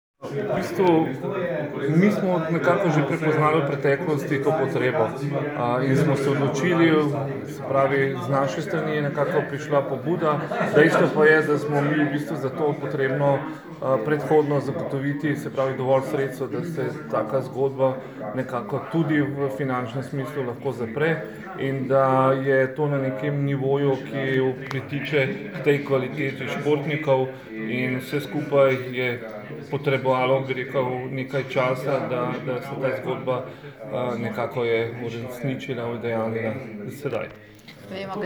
Avdio izjava